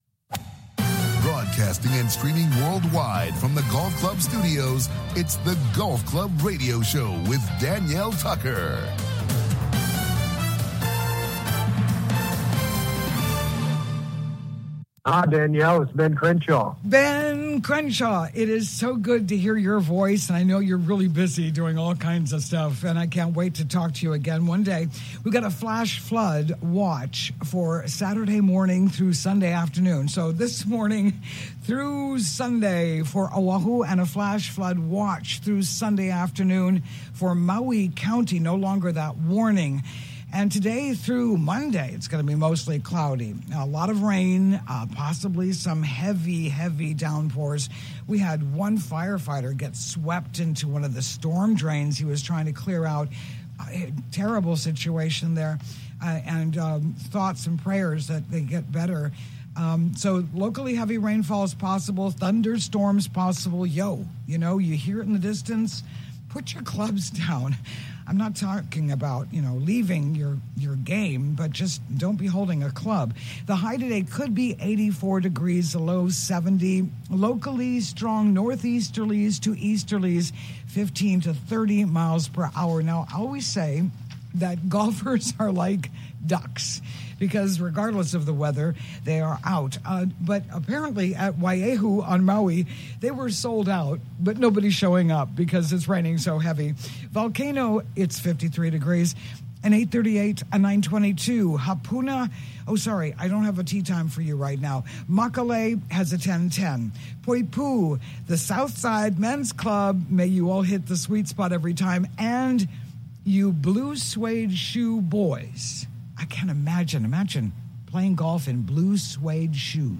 COMING TO YOU LIVE FROM THE RANGE AT THE ALA WAI GOLF COURSE ON OAHU�S LOVELY SOUTH SHORE � WELCOME INTO THE GOLF CLUB HOUSE ON THE ROAD!